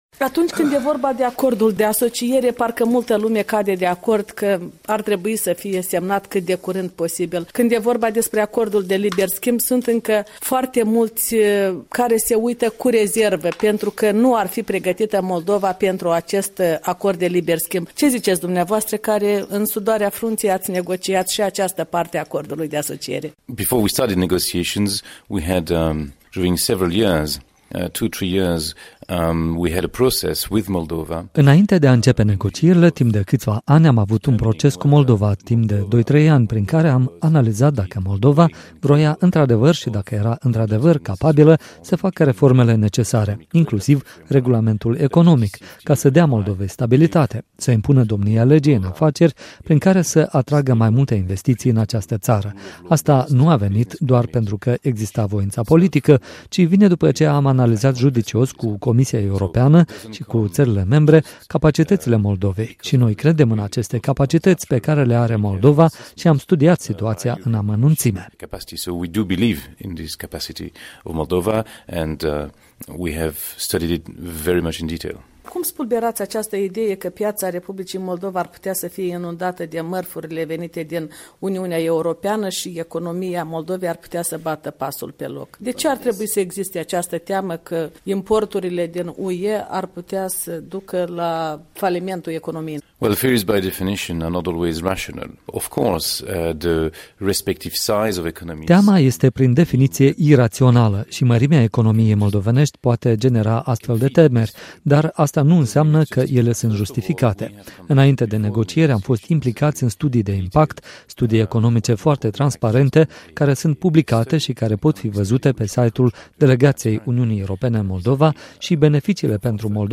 Interviu cu negociatorul principal al UE pentru Acordul de Liber Schimb, Luc Devigne